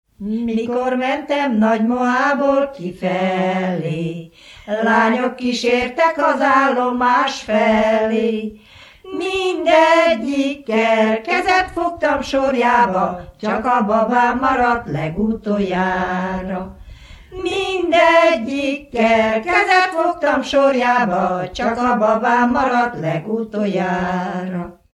Erdély - Nagy-Küküllő vm. - Nagymoha
Műfaj: Lassú csárdás
Stílus: 3. Pszalmodizáló stílusú dallamok